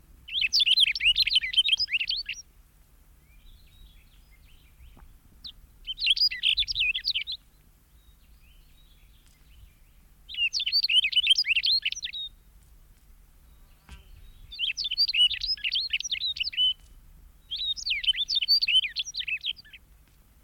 На этой странице собраны звуки пения овсянки — красивые трели и щебетание одной из самых мелодичных птиц.
Звуки, которые издает овсянка